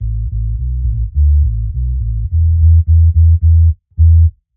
Index of /musicradar/dub-designer-samples/105bpm/Bass
DD_JBass_105_A.wav